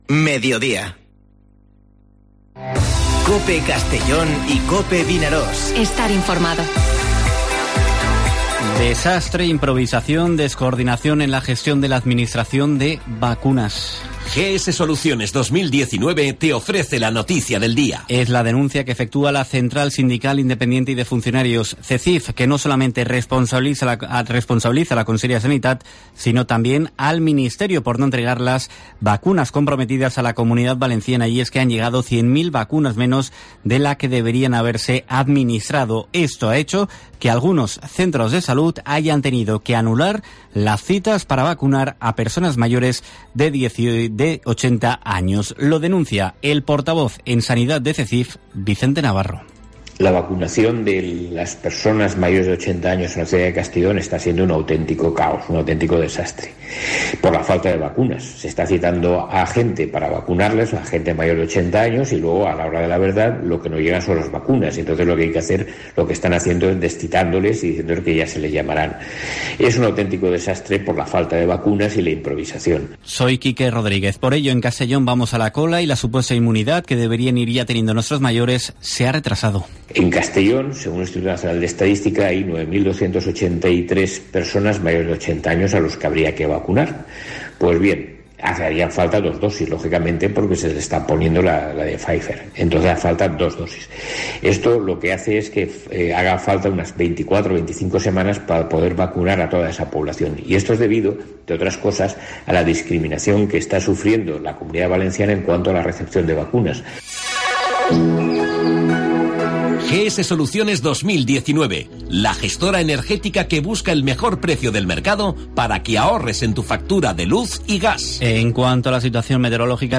Informativo Mediodía COPE en la provincia de Castellón (11/03/2021